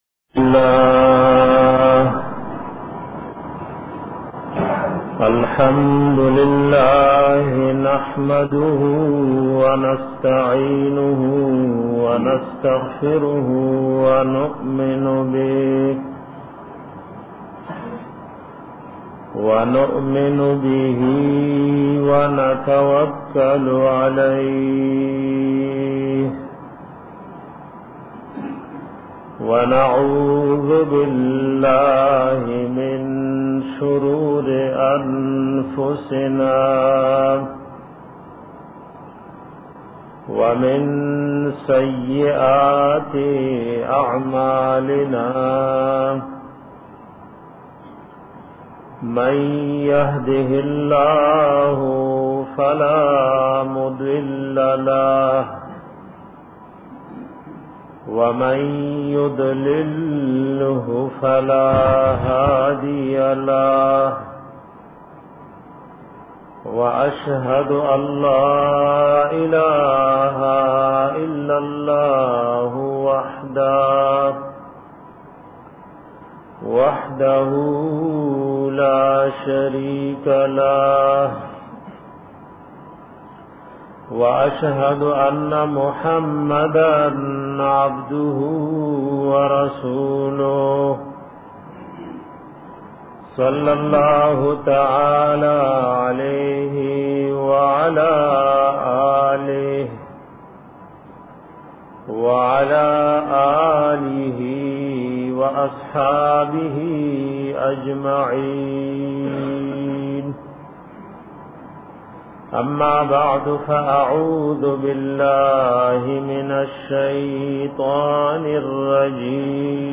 bayan pa wajht da mari ka